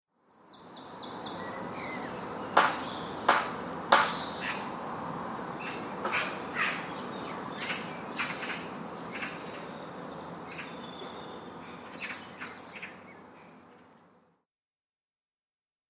دانلود صدای پرنده 42 از ساعد نیوز با لینک مستقیم و کیفیت بالا
جلوه های صوتی